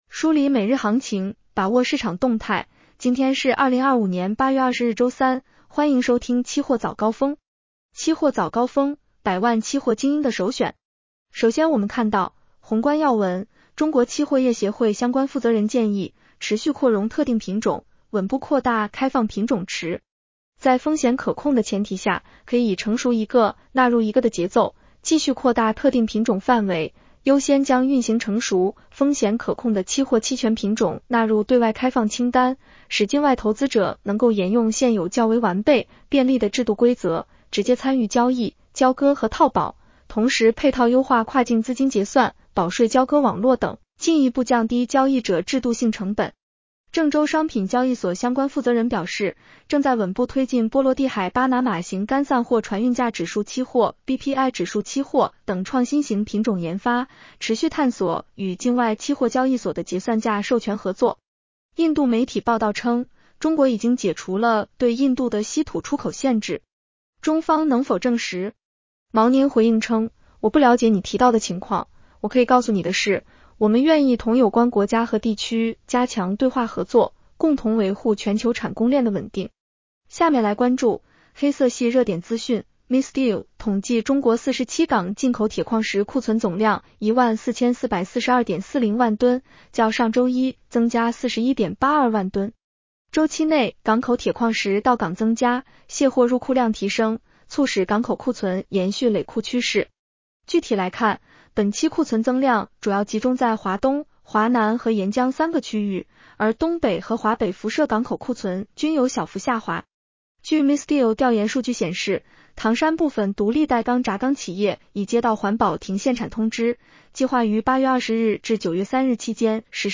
期货早高峰-音频版
期货早高峰-音频版 女声普通话版 下载mp3 宏观要闻 1.